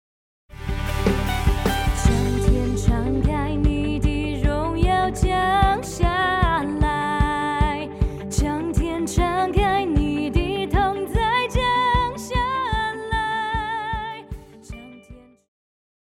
Christian
Pop chorus
Band
Voice with accompaniment